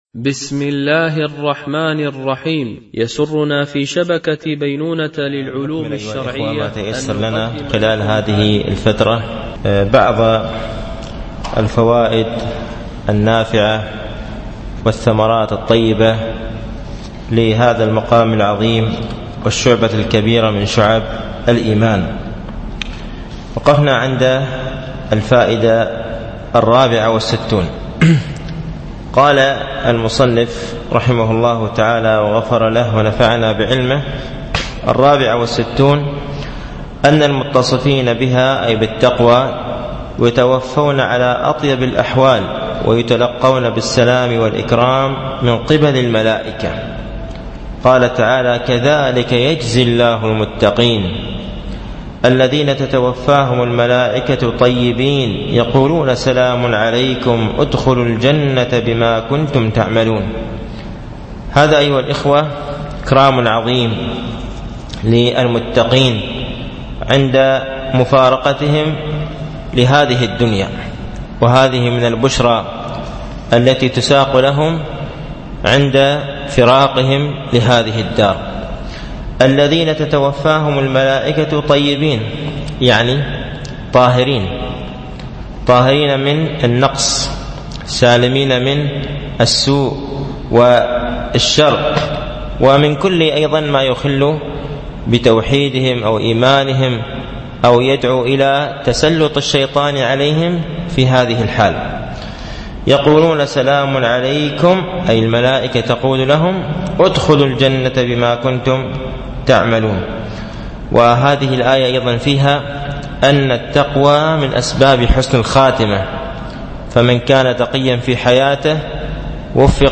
التعليق على رسالة فوائد التقوى في القرآن الكريم ـ الدرس الثاني